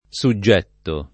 suggetto [ S u JJ$ tto ]